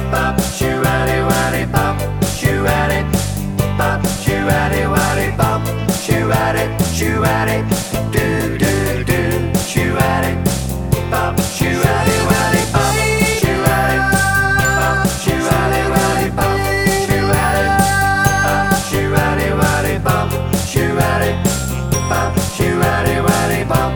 With Count In Pop (1970s) 3:48 Buy £1.50